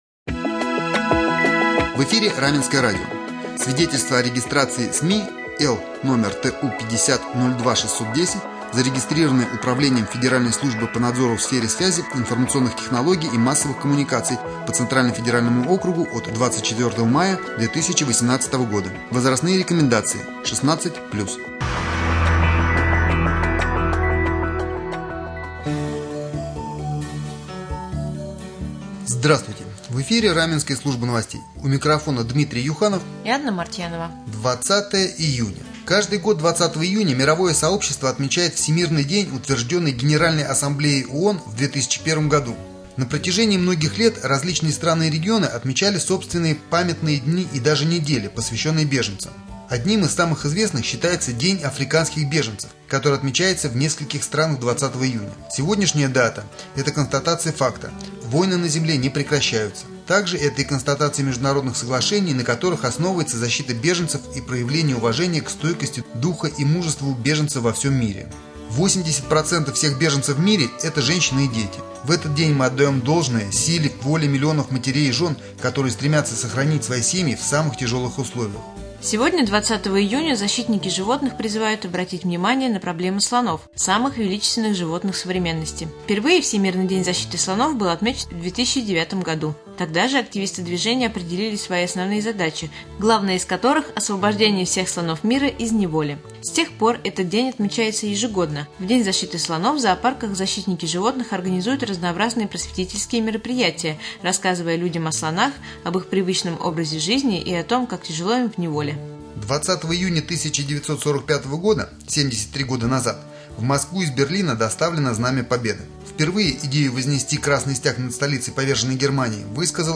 1.Novosti-1-4.mp3